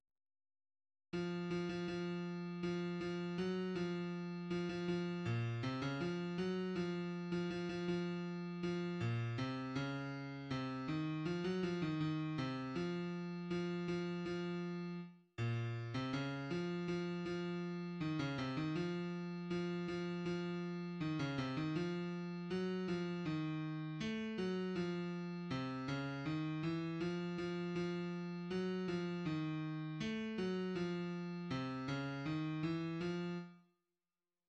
html5media>bass